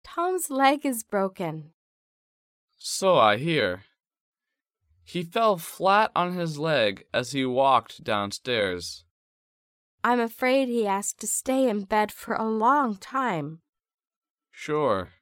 迷你对话：